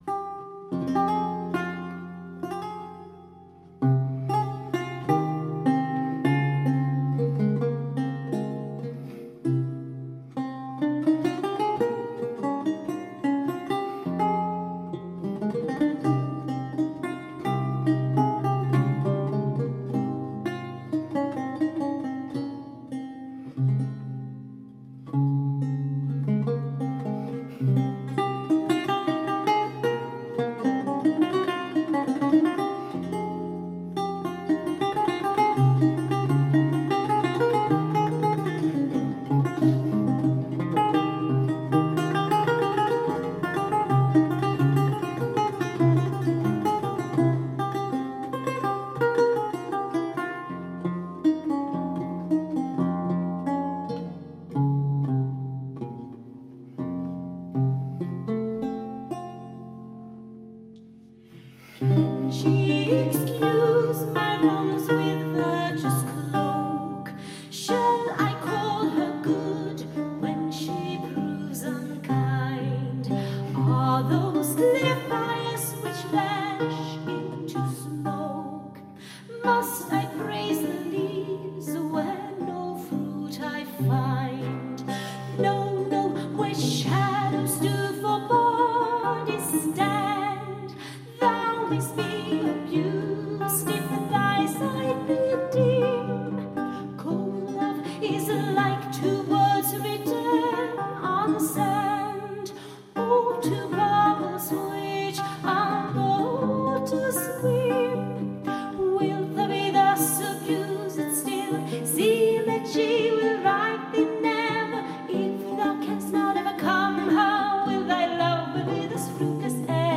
Una incursione nella musica antica del Nord Europa.